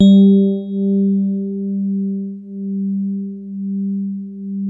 FINE SOFT G2.wav